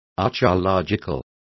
Complete with pronunciation of the translation of archeological.